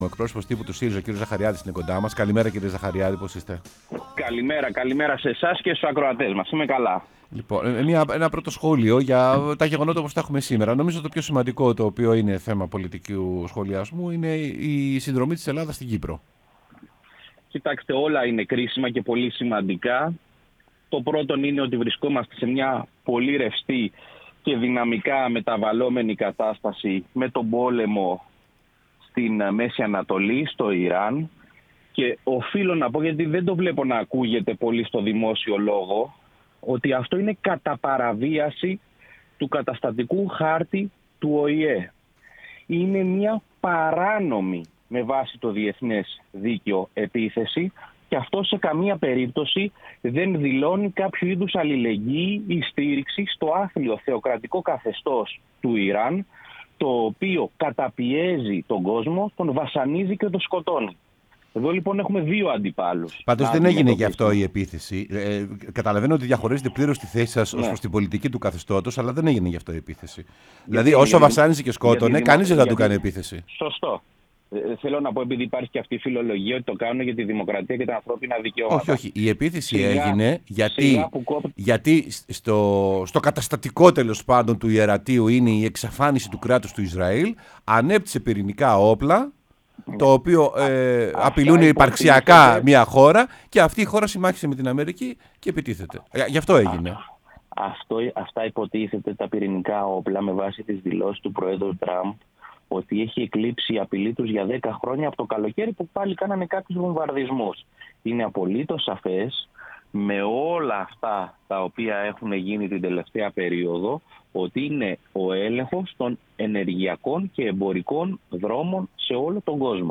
Ο Κώστας Ζαχαριάδης στο ΕΡΤnews Radio 105,8 | 05.03.2026